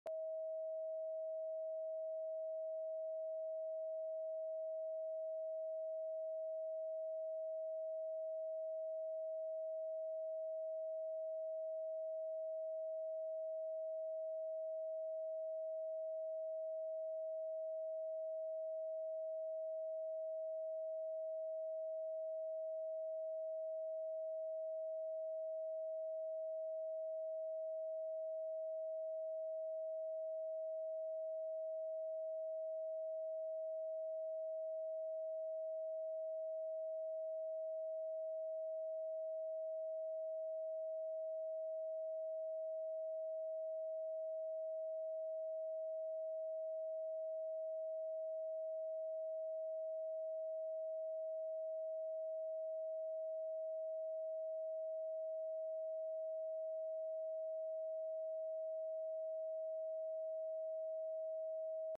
639Hz – Heal Your Heart sound effects free download